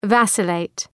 Προφορά
{‘væsə,leıt}